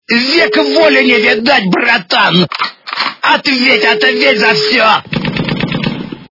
При прослушивании Братэла из зоны - Век воли не видать, братан, ответь за все! с выстрелама автомата качество понижено и присутствуют гудки.
Звук Братэла из зоны - Век воли не видать, братан, ответь за все! с выстрелама автомата